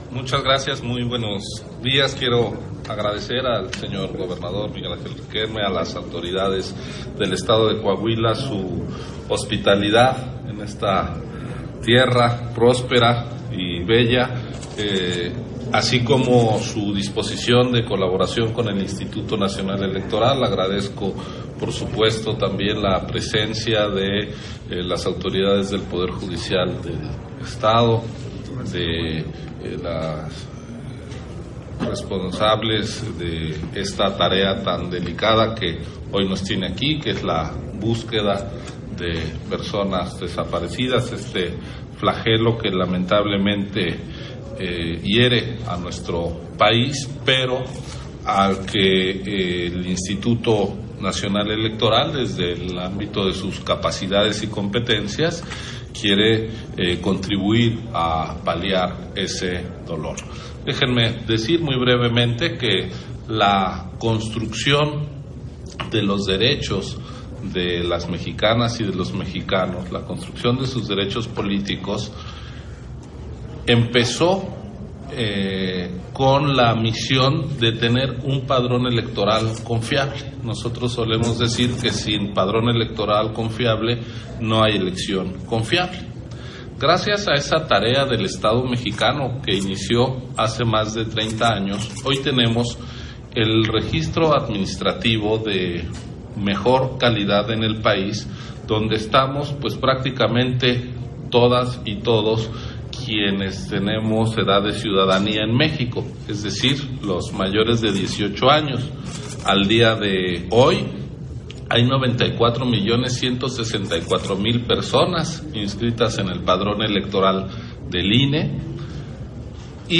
Intervención de Ciro Murayama, en la firma de Convenio de Apoyo y Colaboración INE-Gobierno del Estado de Coahuila, para la obtención de información en la búsqueda e identificación de personas